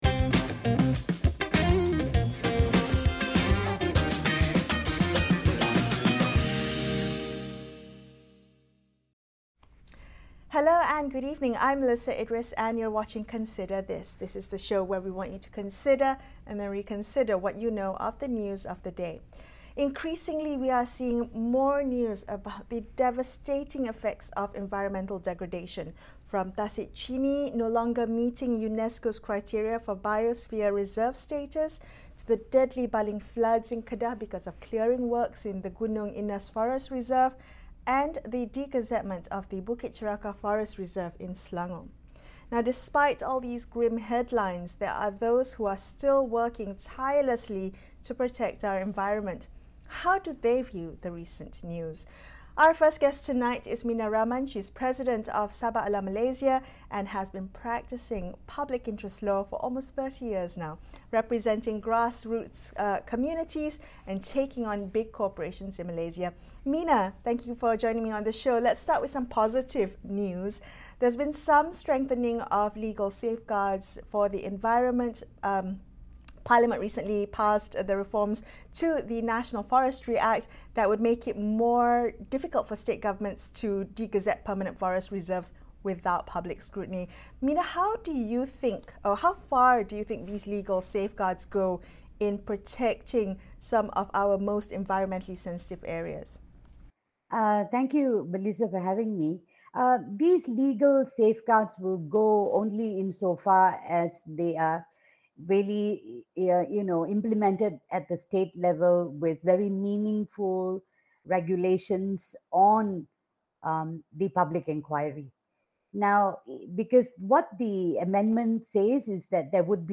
as she speaks to our guests about the work that goes into protecting our environmentally sensitive areas.